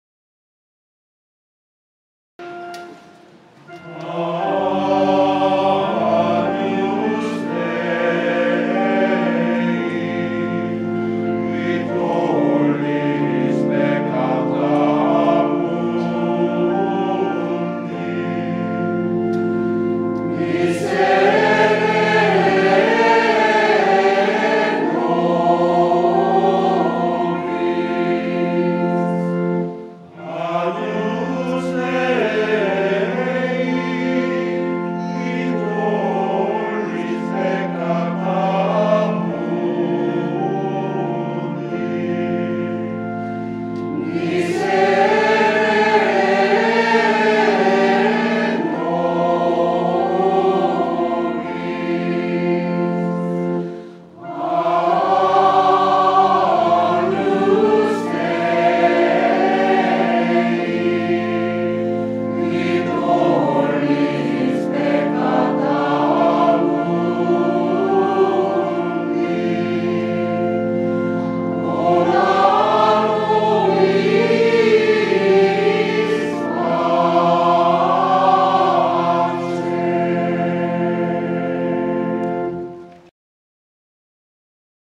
Mass of the Angels, Gregorian Chant